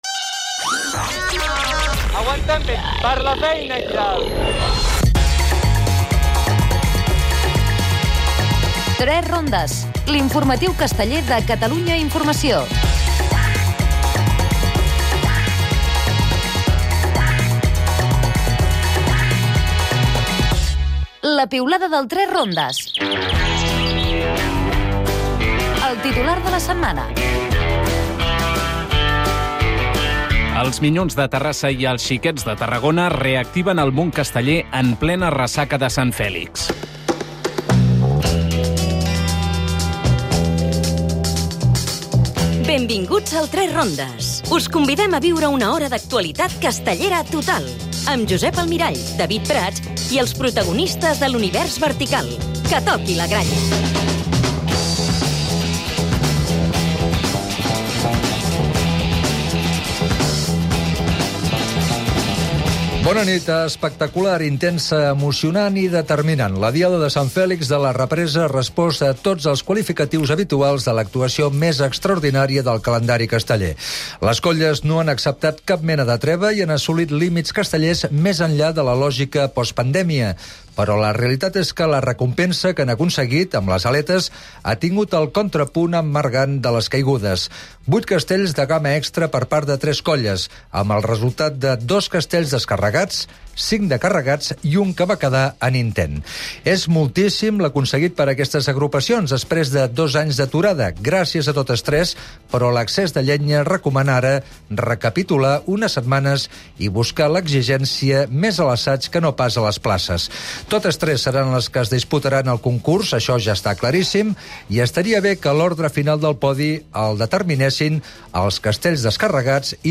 Al "3 rondes" hem estat a les diades de Sabadell i Torredembarra. Anlisi de la diada de Sant Flix de Vilafranca del Peneds. Tertlia